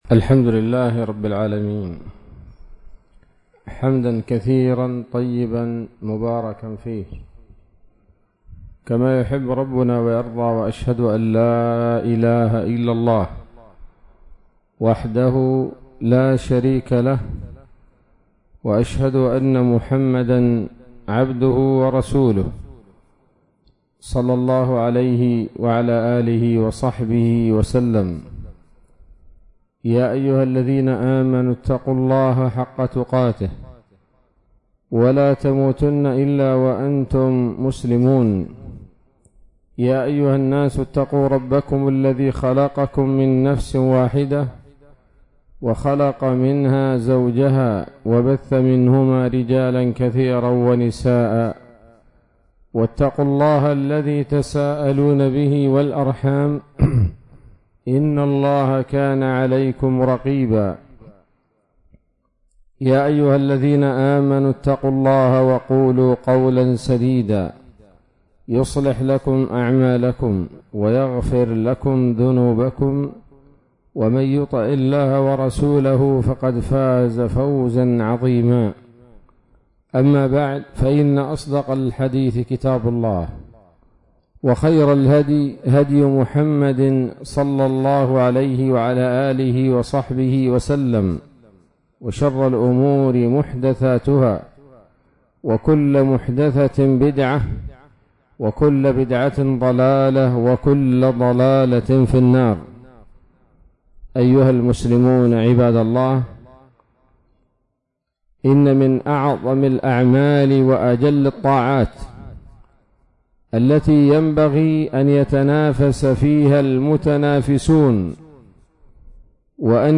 كلمة قيمة بعنوان: (( الاهتمام بنشر العقيدة )) عصر الجمعة 7 ربيع الآخر 1443هـ، بمنطقة السقيا - لحج